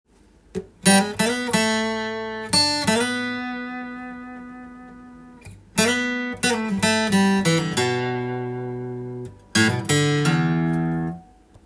この曲のリードギターのメロディーは意外と細かいのが特徴です。